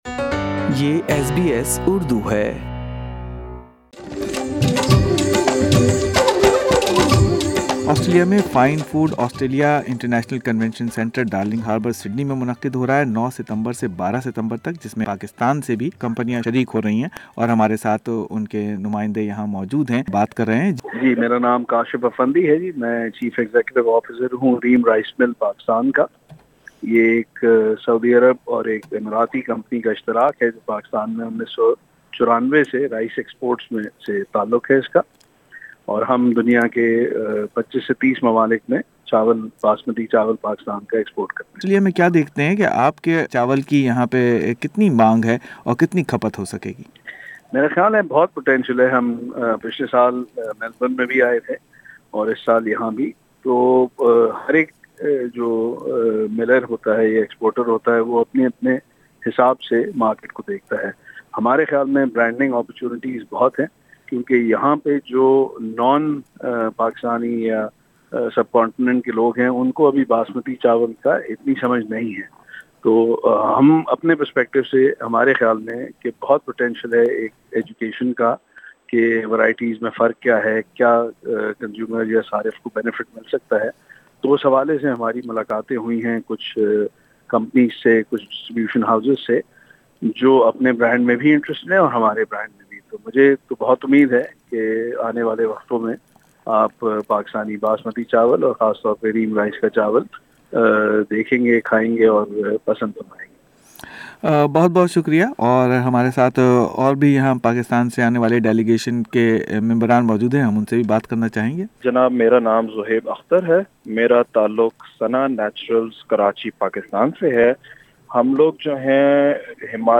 Trade Development Authority of Pakistan (TDAP) has sponsored ten (10) Pakistani companies those are participating in Fine Food Australia being held at the International Convention Centre (ICC) Sydney from 9-12 September 2019. SBS Urdu spoke to representatives Pakistani food product exporters participating in FFA.